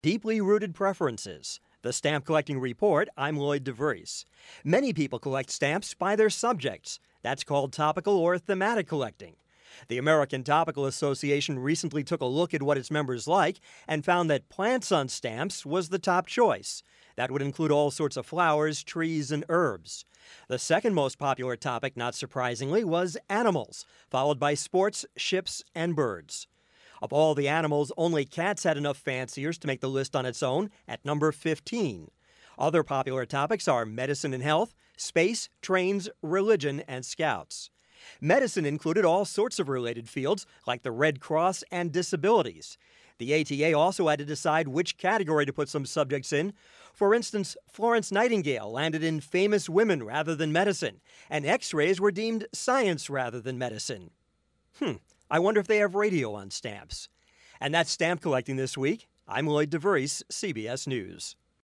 For broadcast on CBS Radio Network stations November 22-23, 2003: